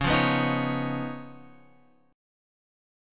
C#9 chord {9 8 9 8 x 9} chord